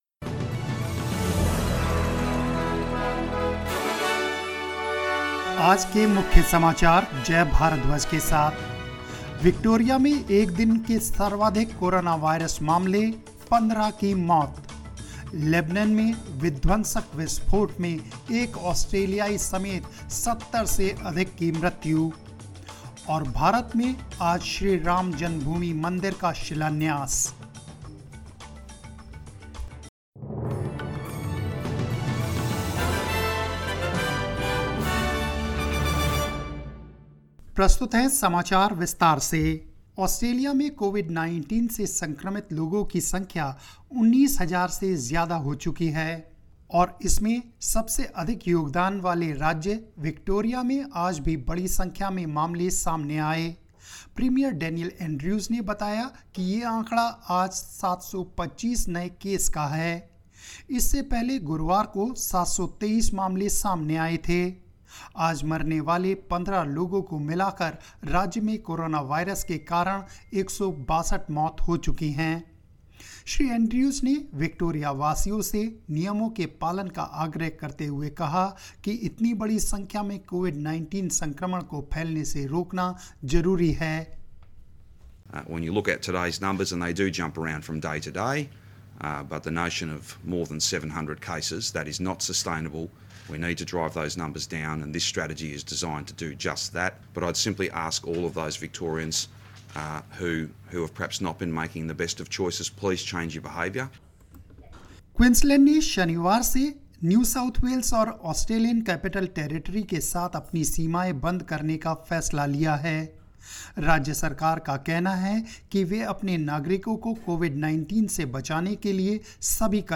News in Hindi 5 August 2020